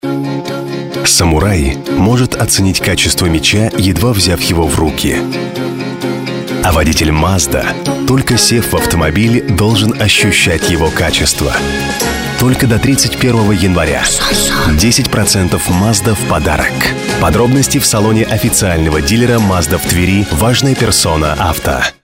Баритон. Молодежный, взрослый, игровой, разноплановый.
Тракт: MICROPHONE NEUMANN TLM 102 PREAMPLIFIER DBX 376 SOUND CARD M-AUDIOPHILE 192